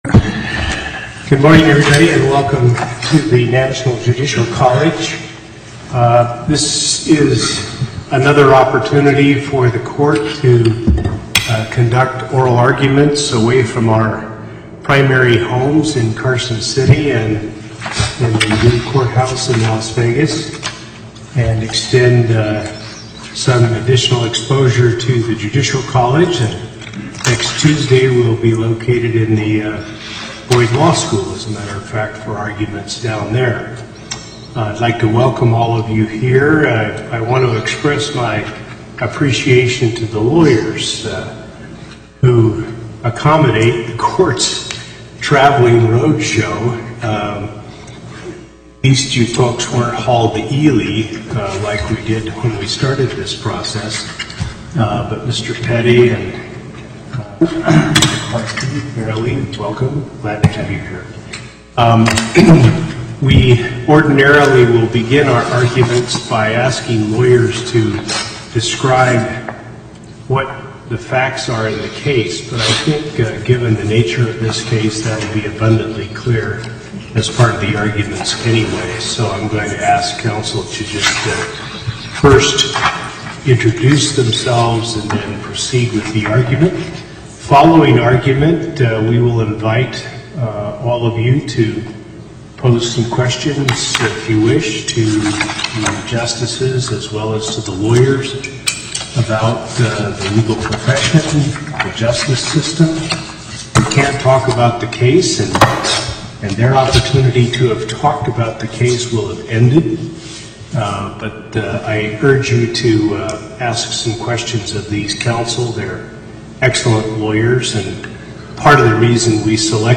Location: National Judicial College Before the Northern Nevada Panel, Justice Hardesty presiding